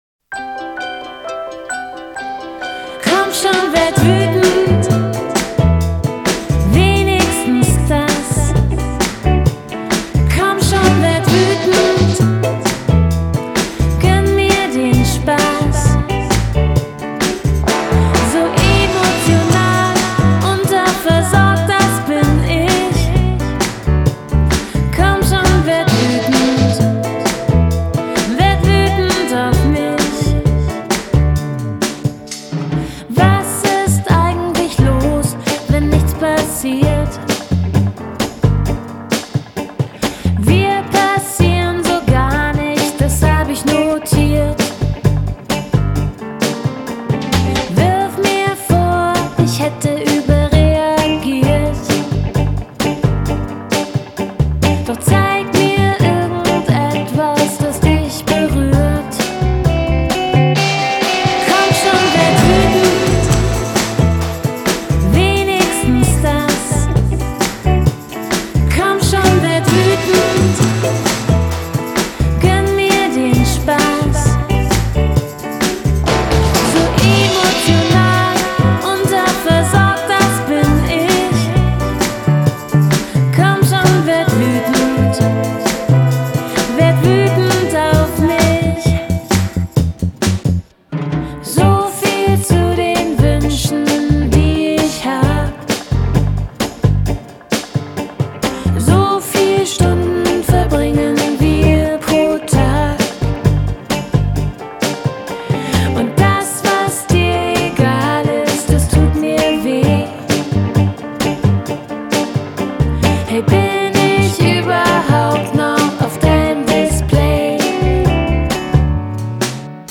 певица, актриса, ведущая
хэппи хардкор, поп